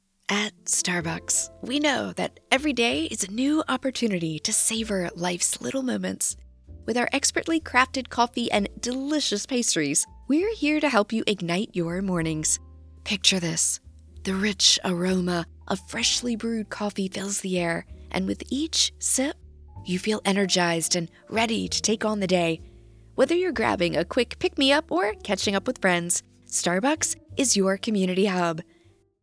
Starbucks - Radio Spot - Warm, Engaging, Assuring, Soothing
General American
Middle Aged